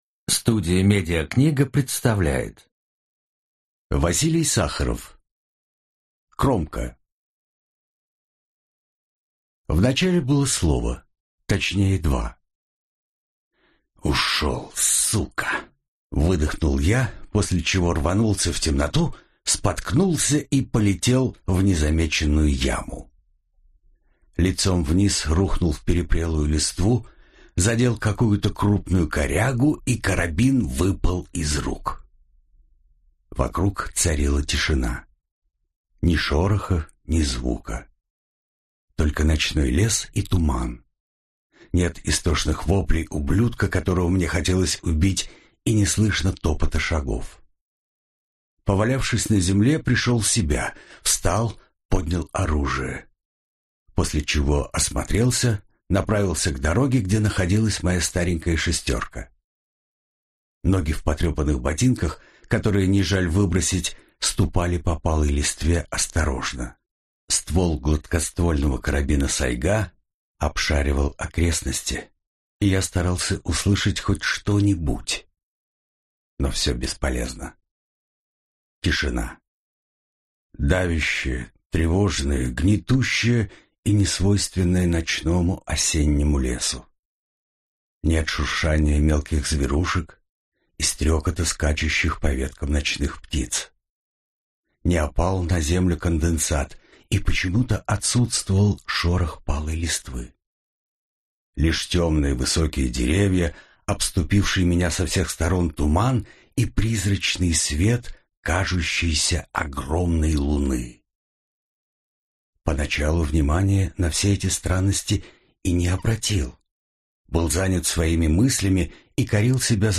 Аудиокнига Кромка | Библиотека аудиокниг